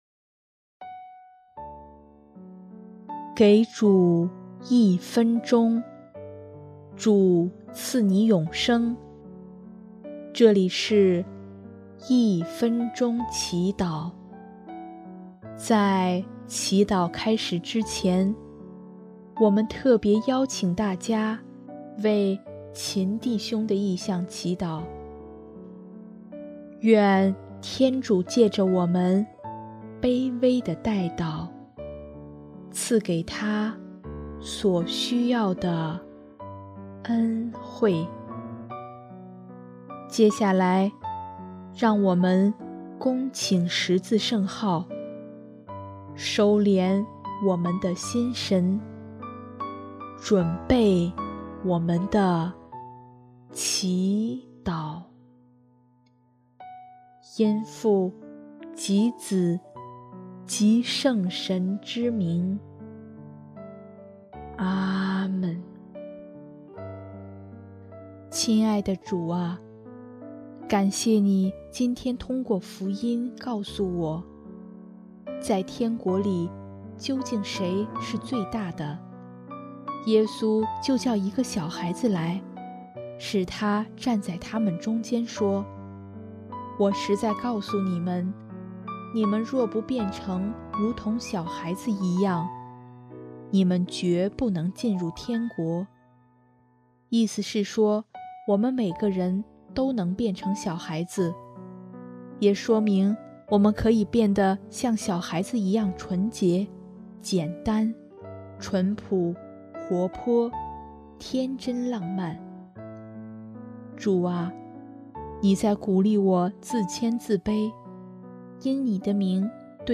音乐：主日赞歌《谦卑的祈祷》